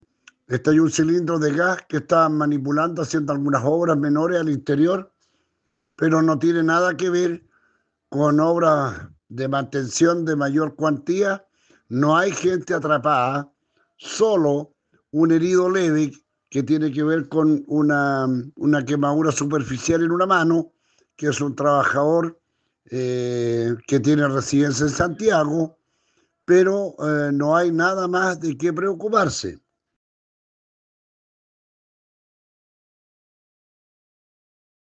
Esta información fue detallada por el alcalde de Quilleco, Claudio Solar, quien afirmó que se estaban realizando trabajos menores.